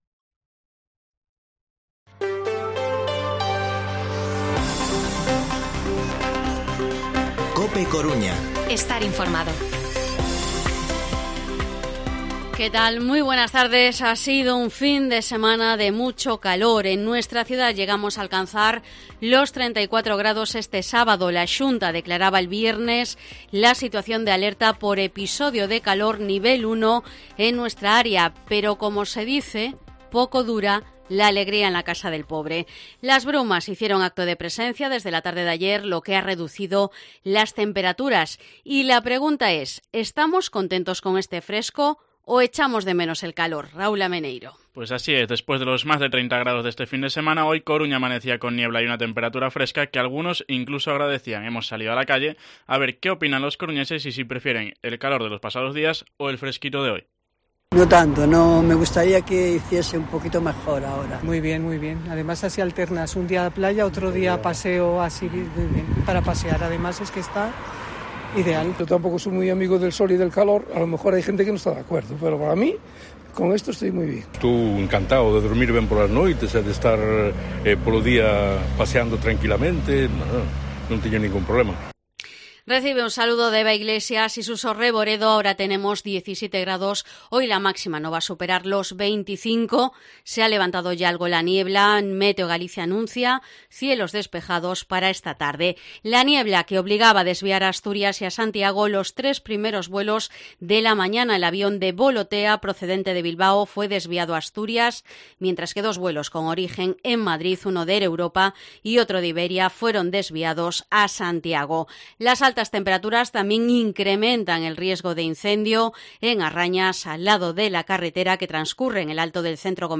Informativo mediodía COPE Coruña lunes, 11 de julio de 2022